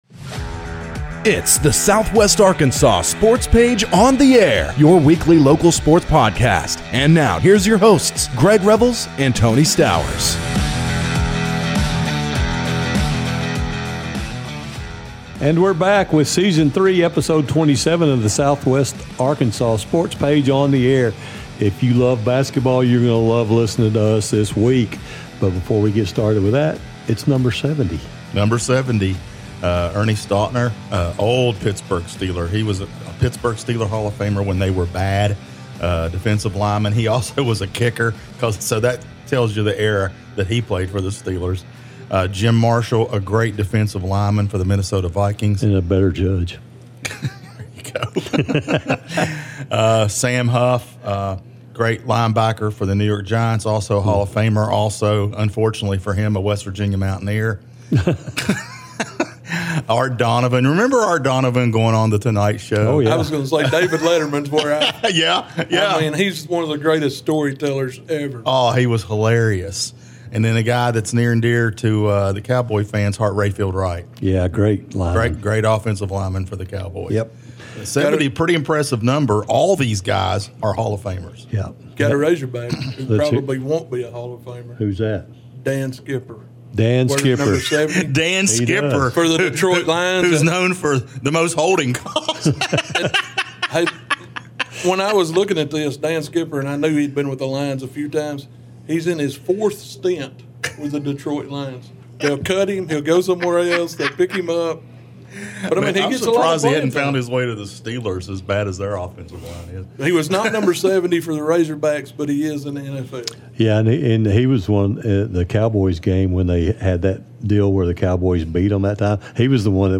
calls into the show